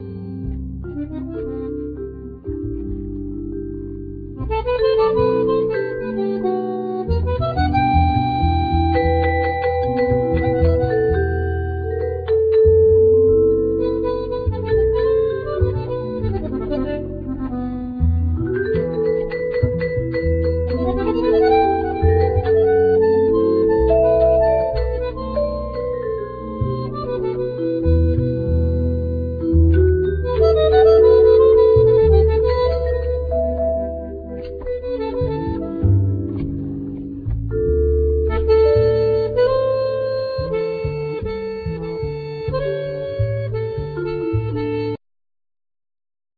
Bandneon,Voice,Percussion
Acoustic & Electric Bass
Marimba,Vibraphone,Percussion